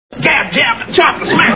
Andy Griffith Show Sound Bites (Page 3 of 4)